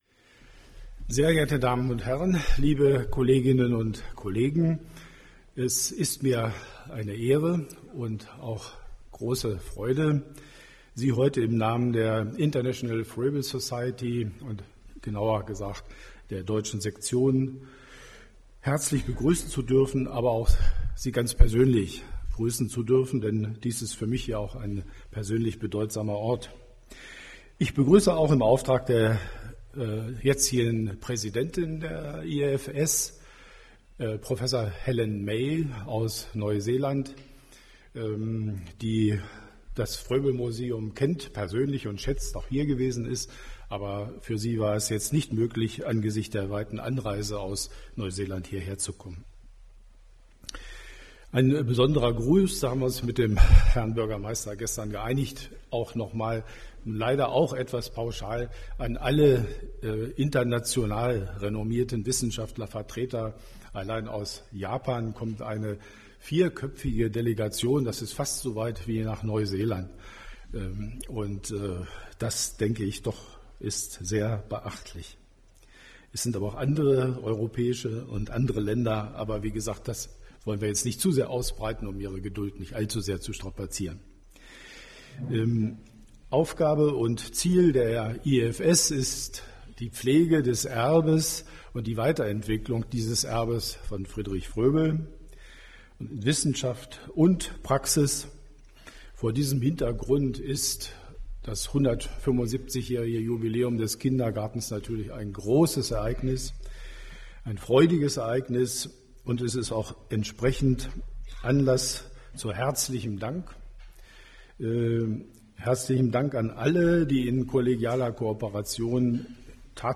Begrüßung Frank Persike , Bürgermeister Bad Blankenburg